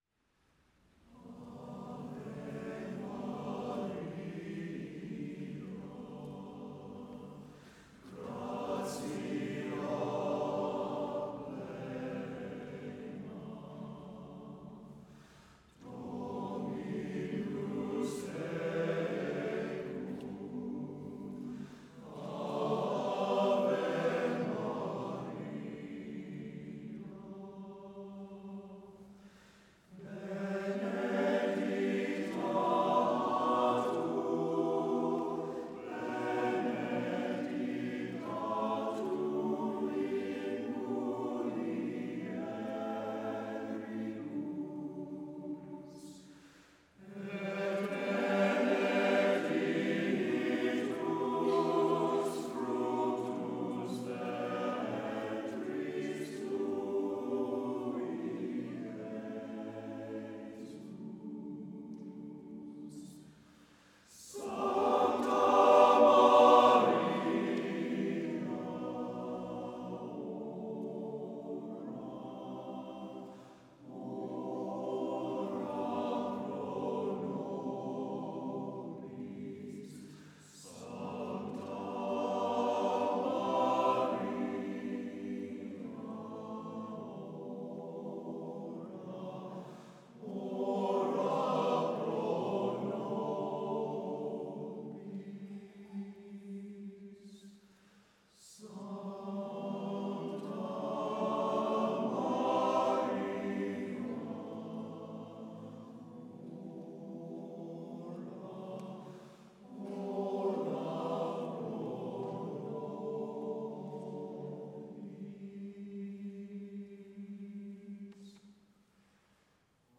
Spring 2006 — Minnesota Valley Men's Chorale